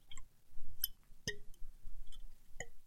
Здесь собраны необычные аудиозаписи: переливы волшебных зелий, таинственные всплески и другие мистические эффекты.
Эликсир плещется в бутылочке